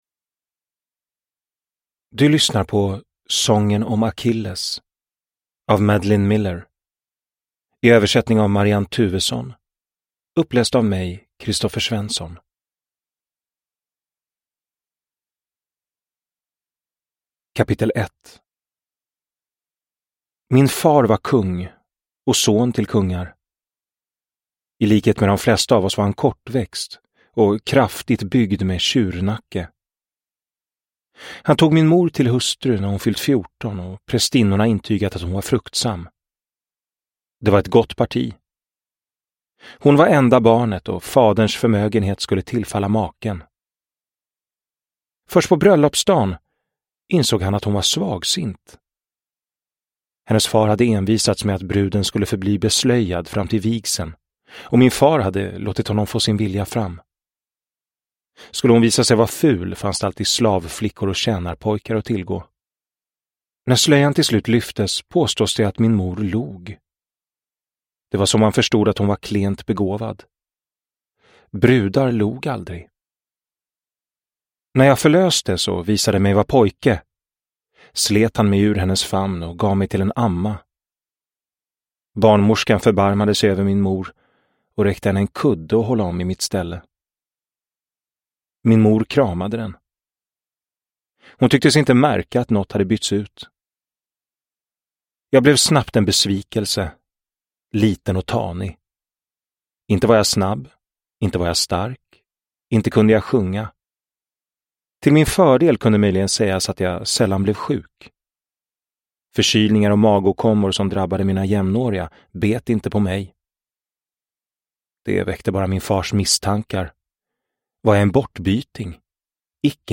Sången om Akilles – Ljudbok – Laddas ner